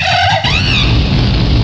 cry_not_infernape.aif